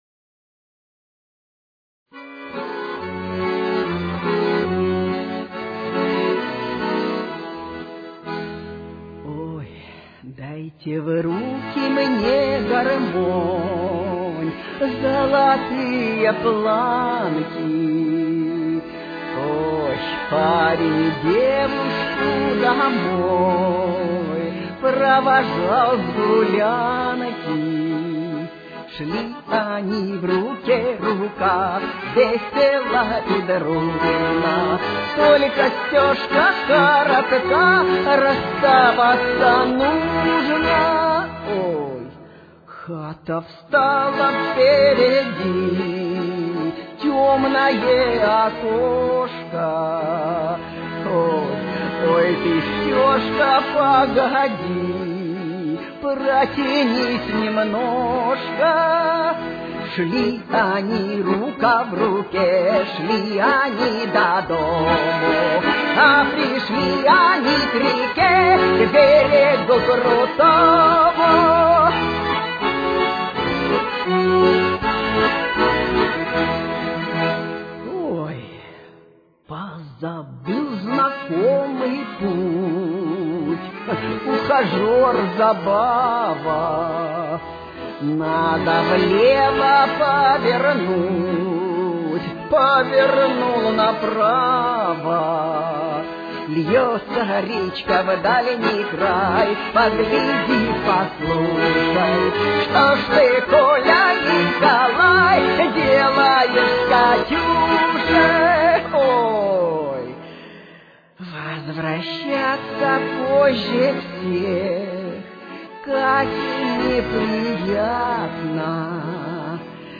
Ми мажор.